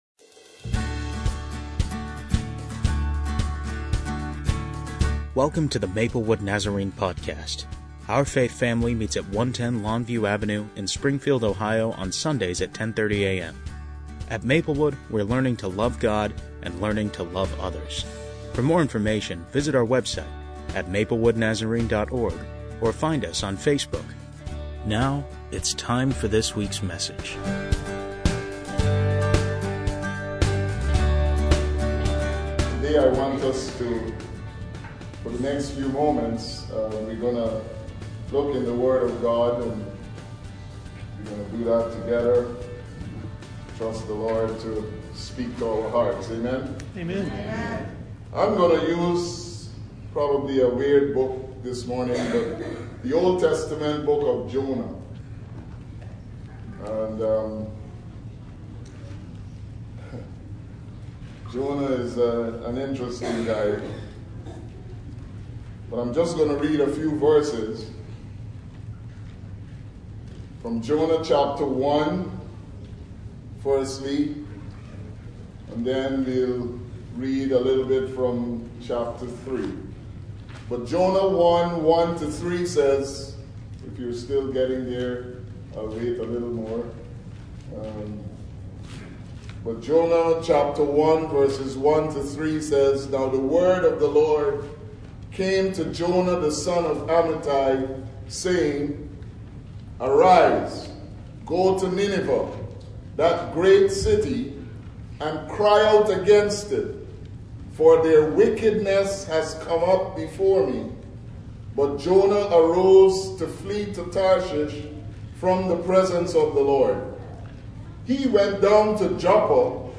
Faith Promise is our way of committing to give regularly to support world missions work through the global Church of the Nazarene. We hope you enjoy this inspiring talk.